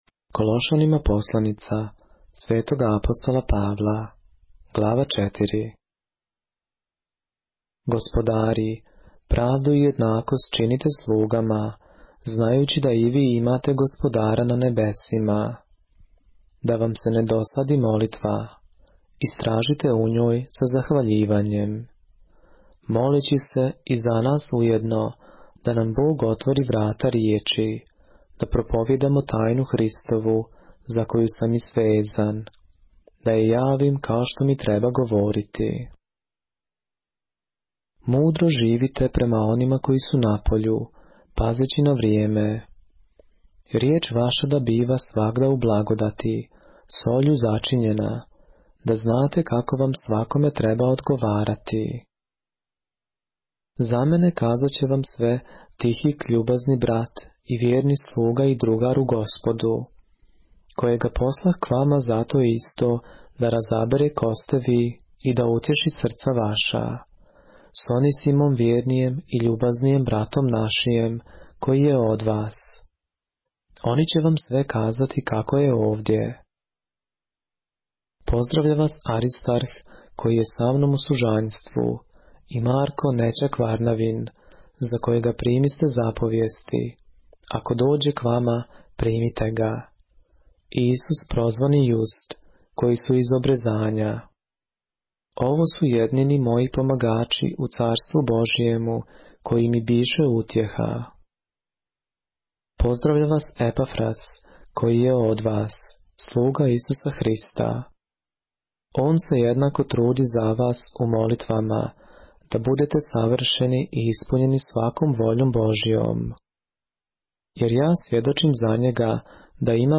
поглавље српске Библије - са аудио нарације - Colossians, chapter 4 of the Holy Bible in the Serbian language